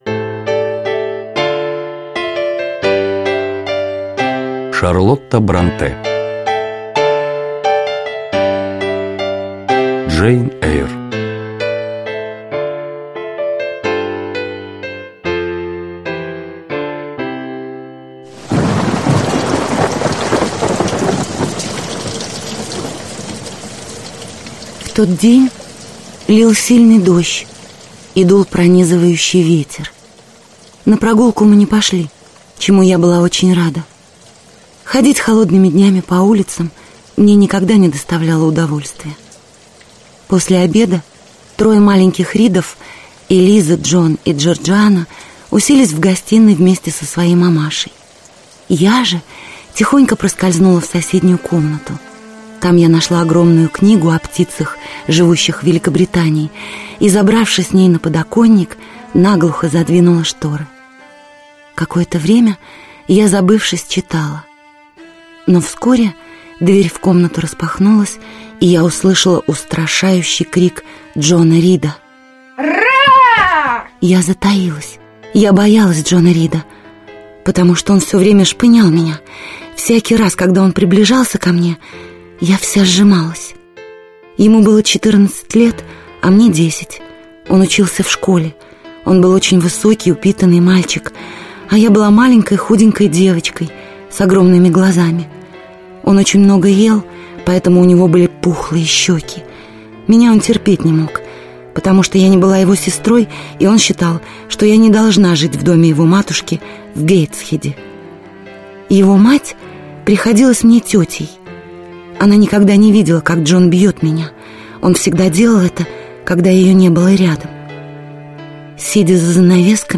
Аудиокнига Джейн Эйр (спектакль) | Библиотека аудиокниг